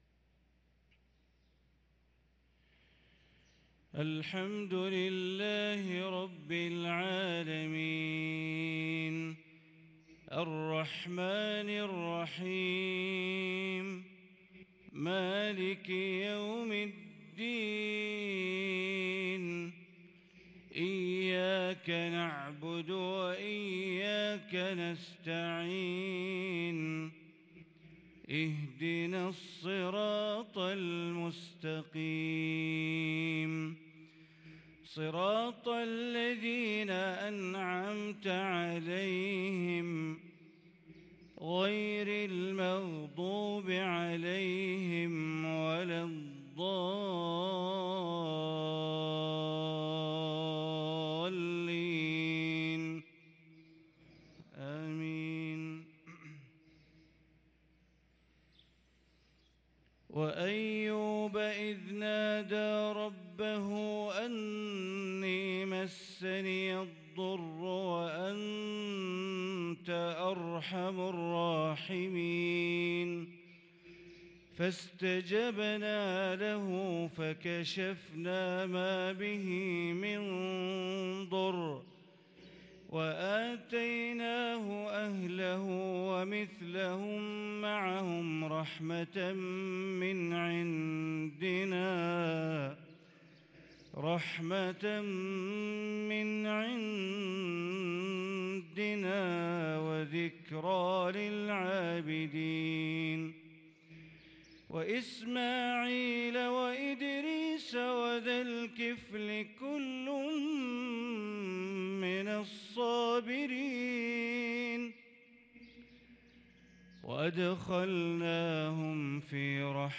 صلاة الفجر للقارئ بندر بليلة 12 صفر 1444 هـ